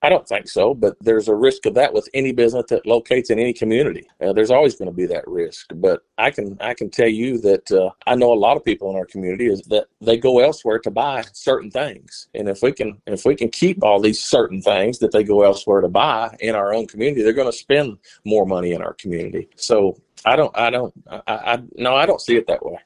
County Judge Kevin Litty, spoke with KTLO News to discuss the parameters of the fund.